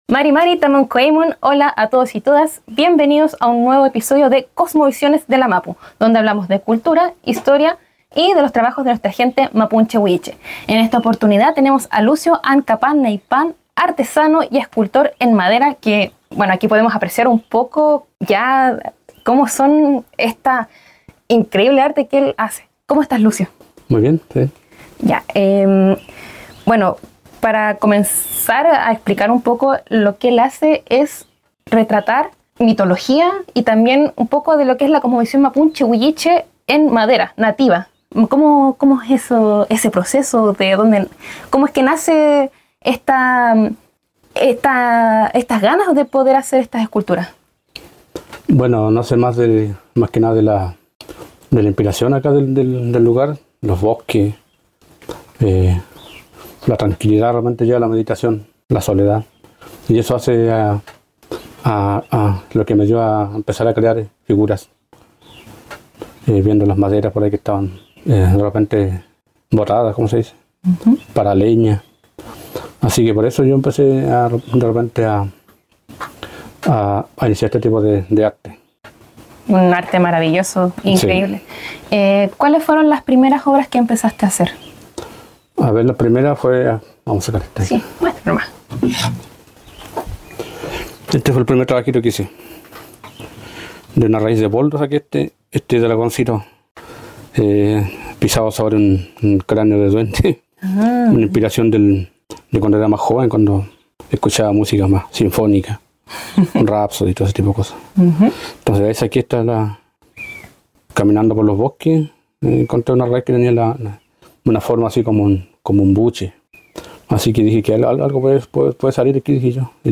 En una conversación reveladora